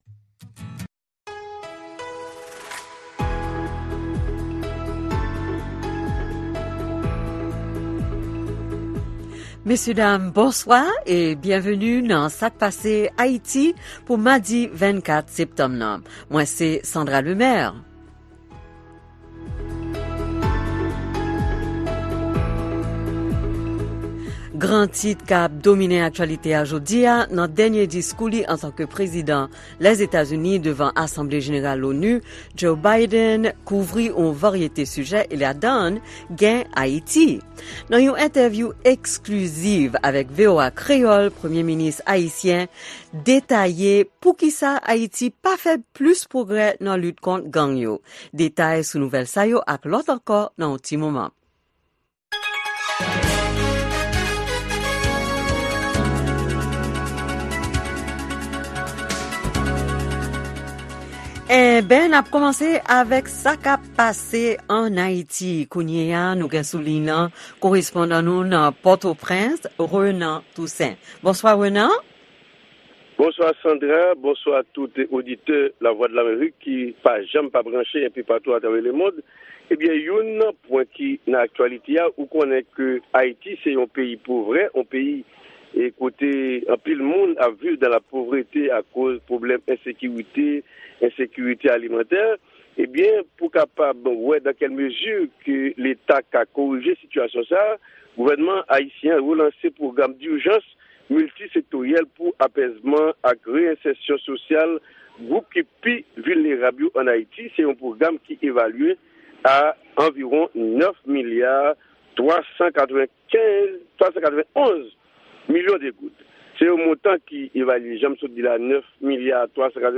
ekstre entevyou esklisiv ak PM Garry Conille, Nouvel Entenasyonal - Biden pale sou Ayiti nan diskou li devan LONU epi sakap pase Madi nan Asanble Jeneral LONU.